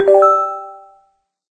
chime_2.ogg